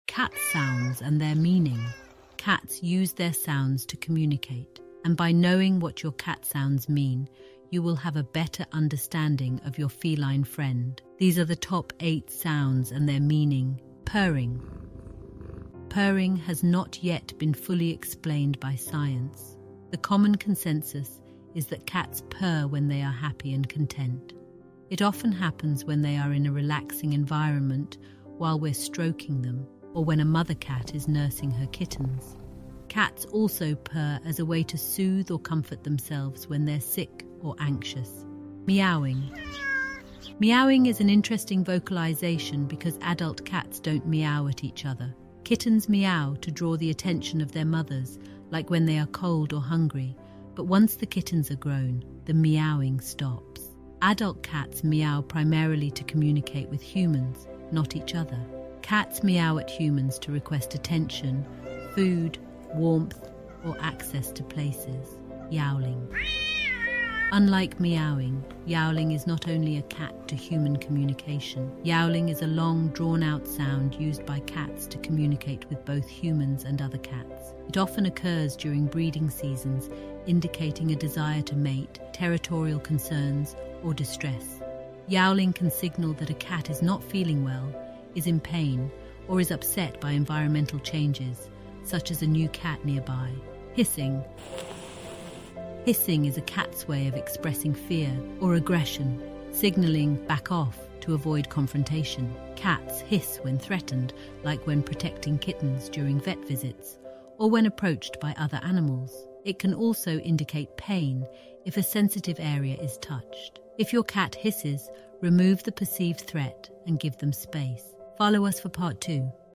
cat sounds and their meaning